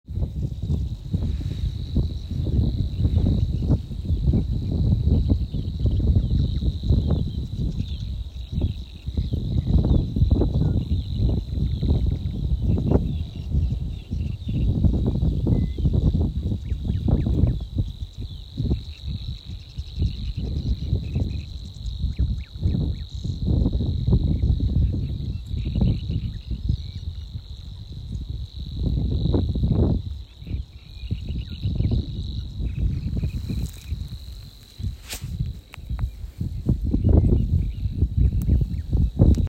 соловьиный сверчок, Locustella luscinioides
Administratīvā teritorijaBabītes novads
СтатусСлышен голос, крики
ПримечанияDzied niedrēs vienlaidus trrrrrrrrr